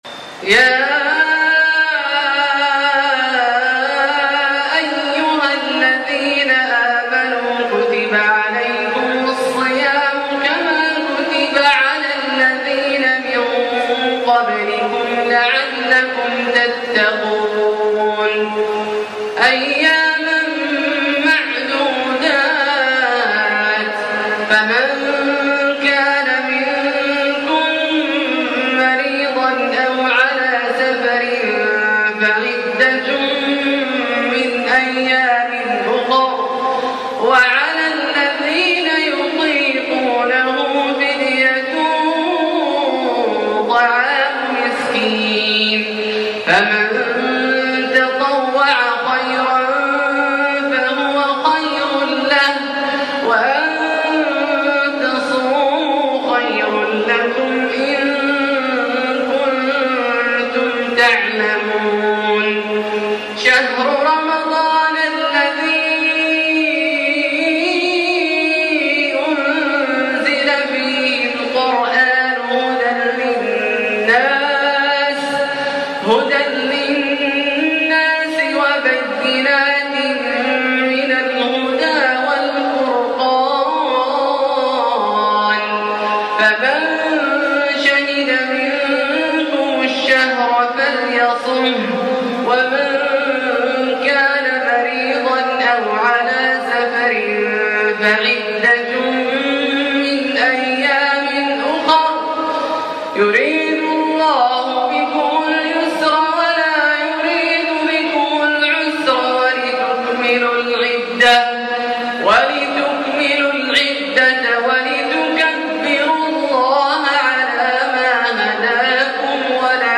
آيات الصيام ١٤٣٩ من سورة البقرة {183-185} > ١٤٣٩ هـ > الفروض - تلاوات عبدالله الجهني